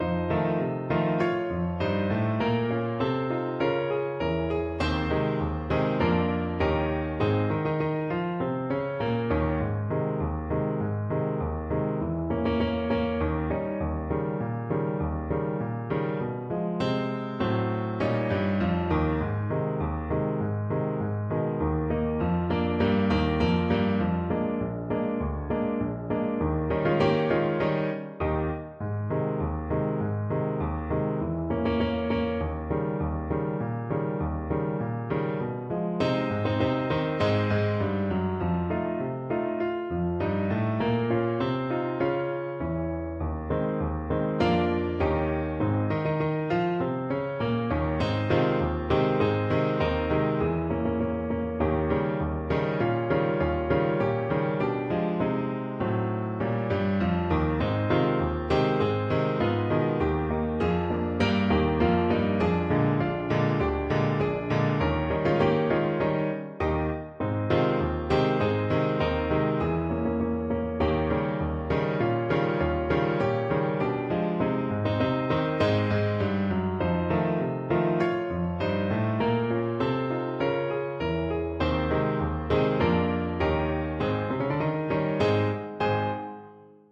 2/4 (View more 2/4 Music)
Tempo di Marcia
Pop (View more Pop Trumpet Music)